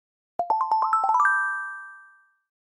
Звуки Gmail уведомлений скачать - Zvukitop